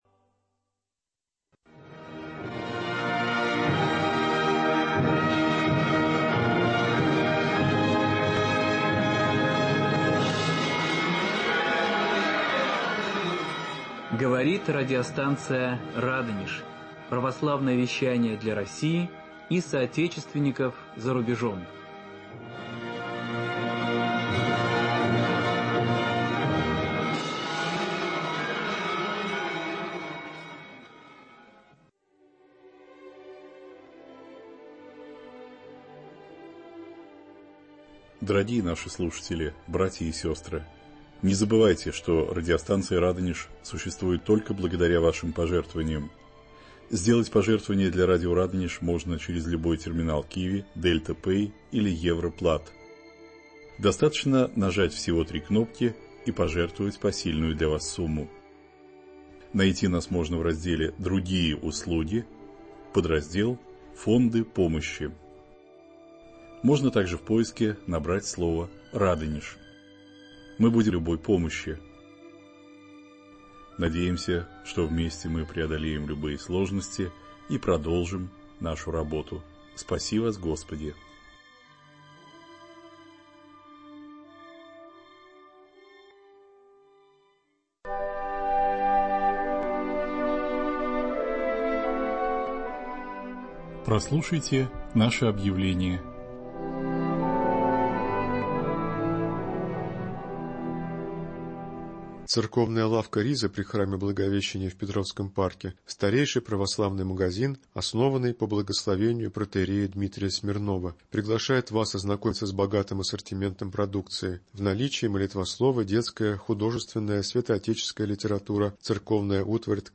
В прямом эфире